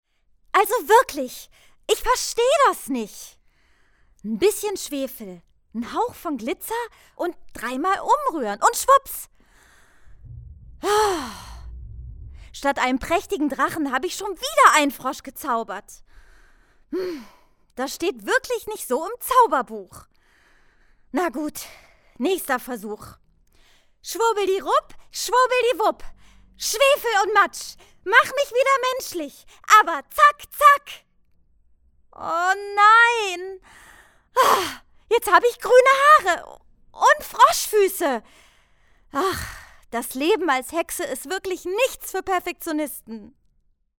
Studiosprechen
Sprachdemos